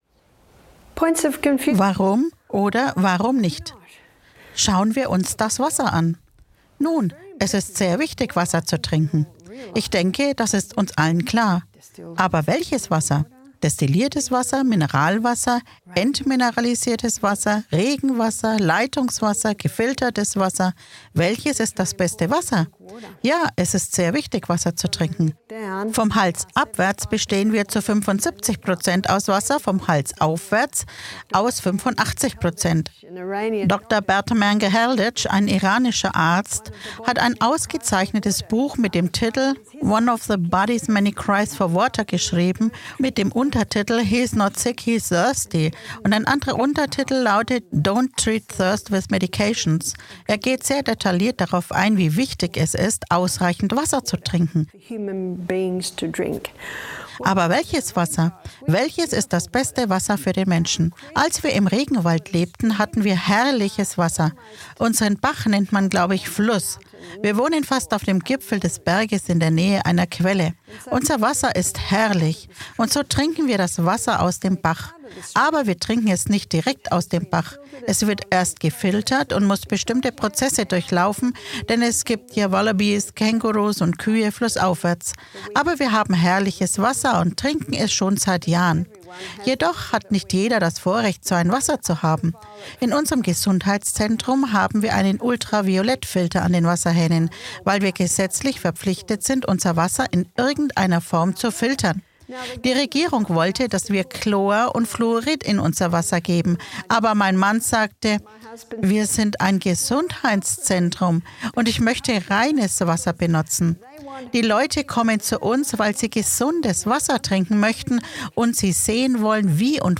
Erforschen Sie die entscheidende Frage: Welches Wasser ist das beste für die Gesundheit? Der Vortrag geht der Bedeutung des richtigen Wassers auf den Grund und beleuchtet, wie Filter, Mineralien und Verpackungen die Gesundheit beeinflussen.